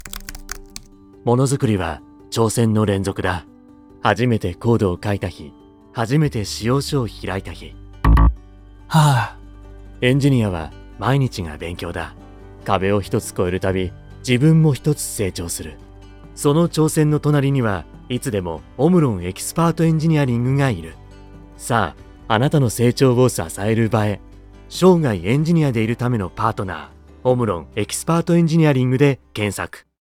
音声CM オムロン エキスパートエンジニアリング採用プロモーション（オムロン エキスパートエンジニアリング株式会社様）